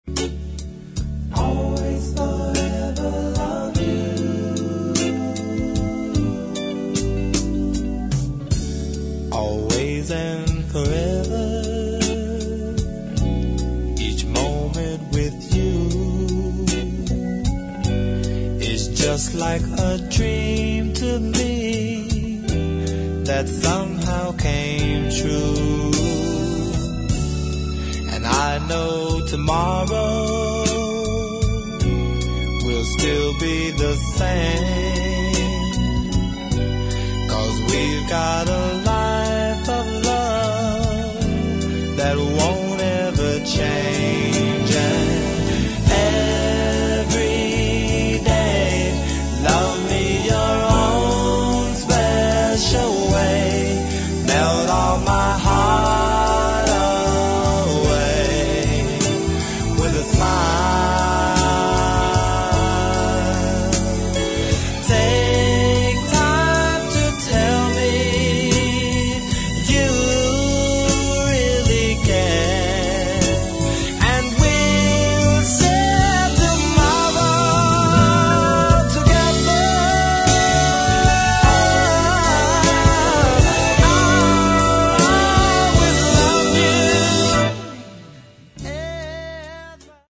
[ DISCO / FUNK ]